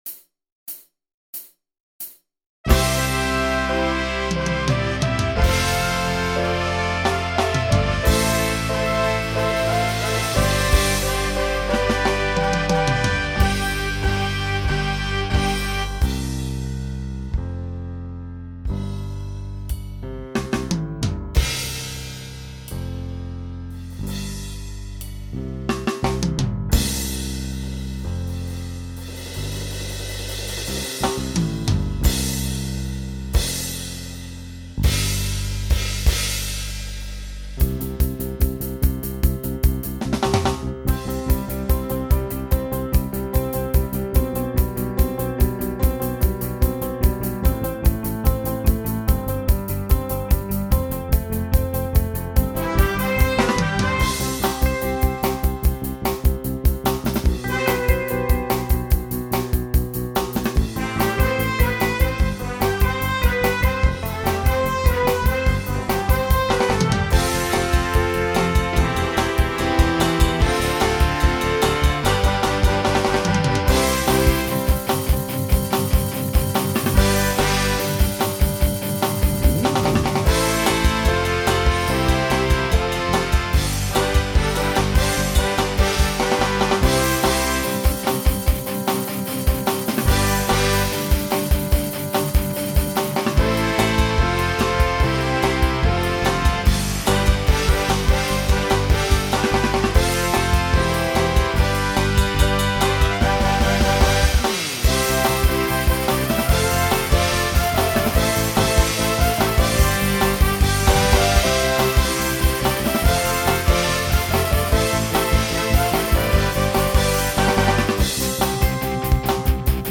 Voicing SSA Instrumental combo Genre Country